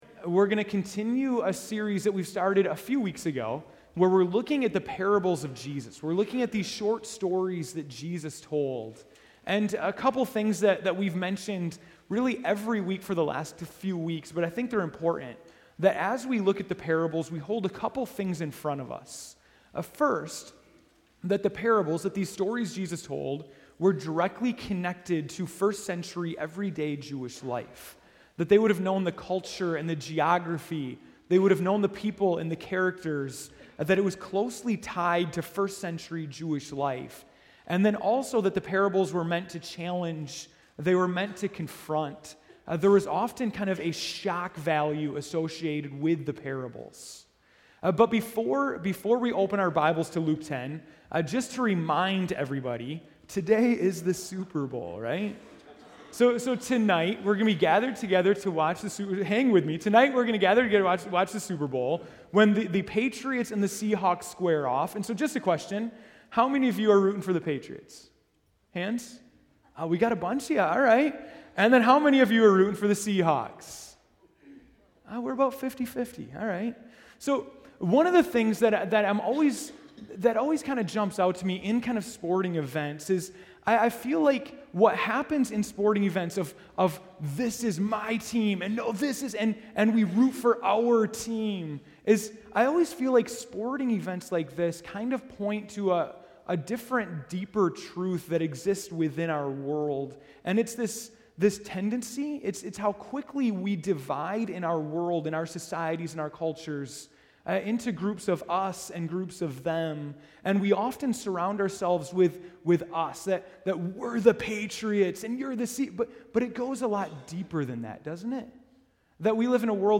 February 1, 2015 (Morning Worship)